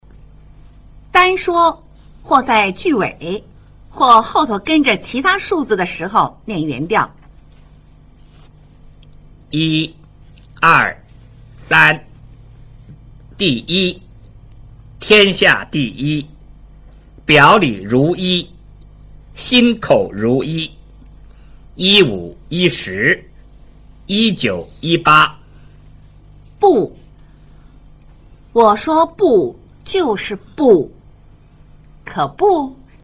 1.2 甚麼時候唸原調
單說, 或在句尾, 或者後面跟著其它數字的時侯唸原調。